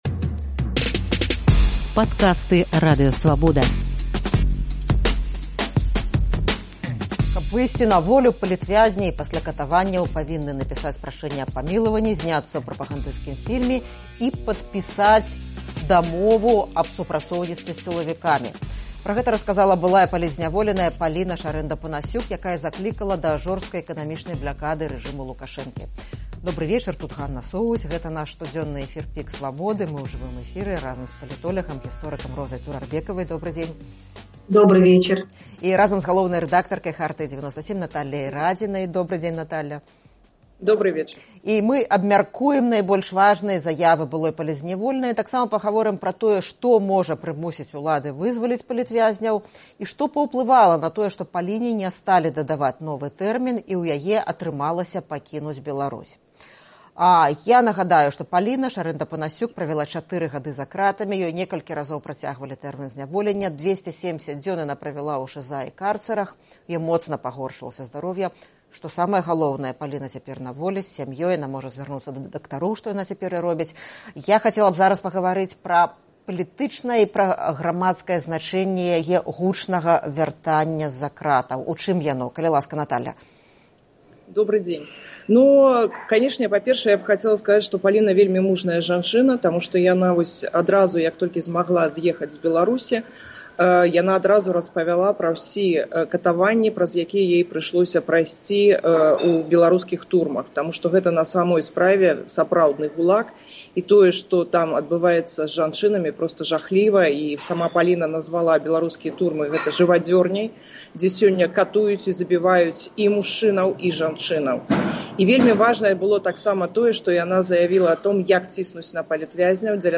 У жывым эфіры «ПіКу Свабоды»